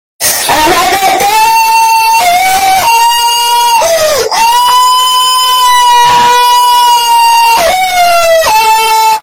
Sound Effect
Earrape